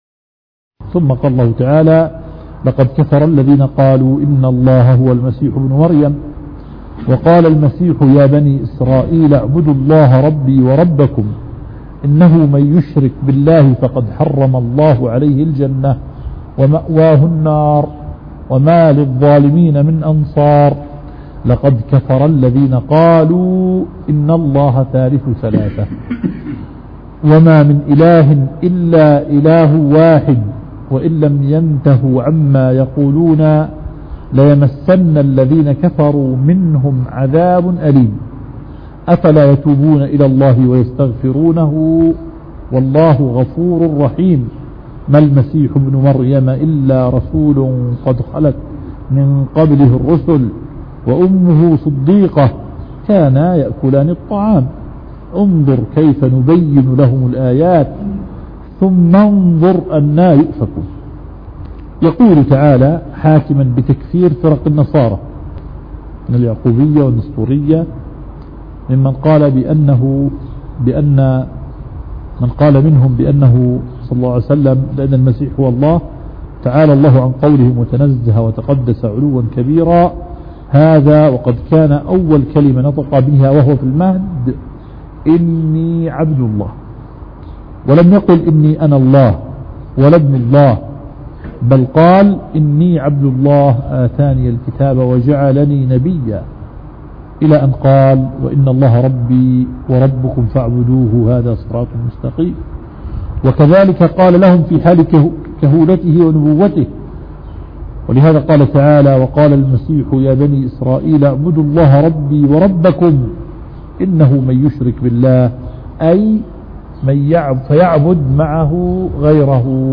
التفسير